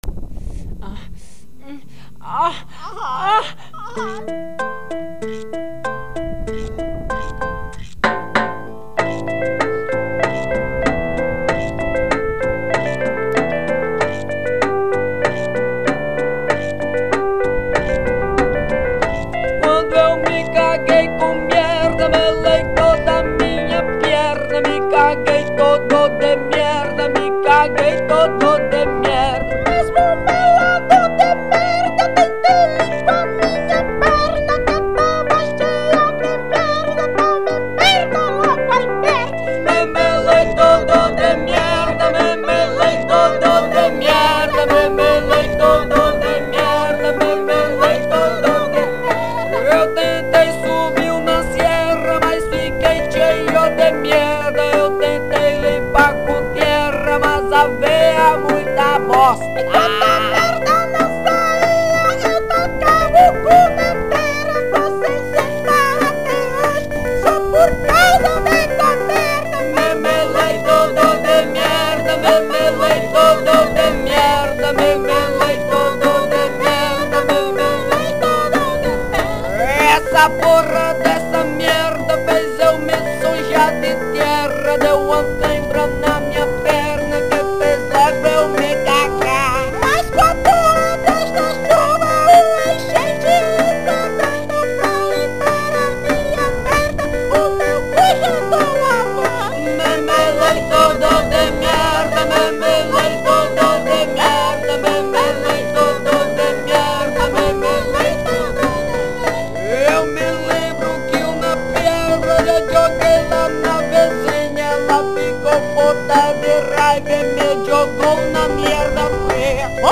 EstiloParódia / Comédia